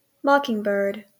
Ääntäminen
US RP : IPA : /ˈmɒkɪŋbɜːd/ GA: IPA : /ˈmɑkɪŋˌbɝd/